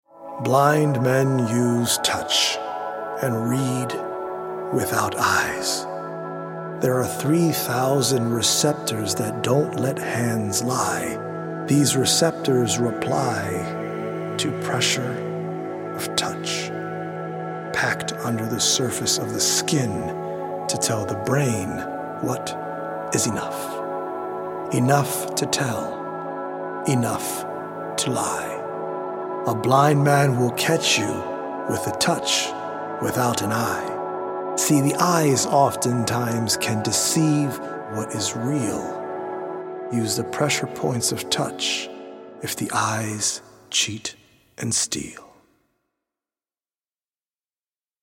audio-visual poetic journey through the mind-body and spirit
healing Solfeggio frequency music
EDM producer